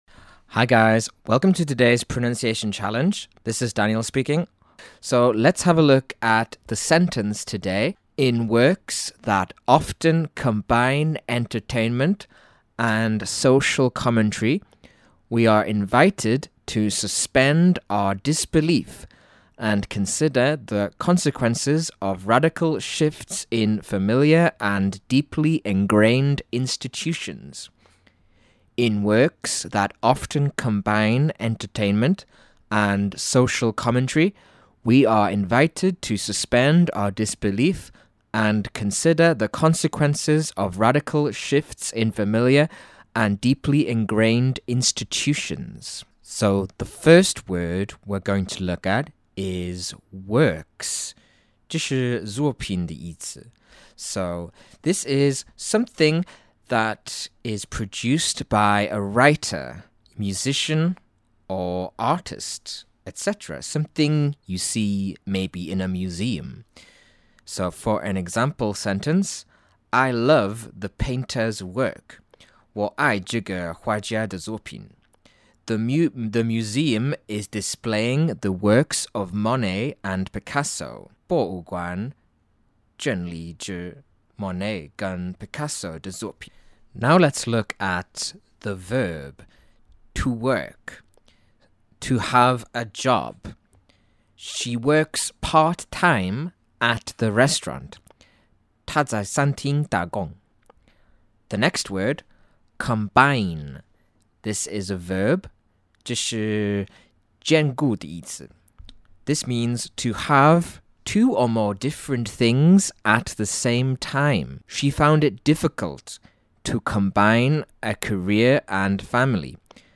台湾籍旅美老师今天交给我们的句子是：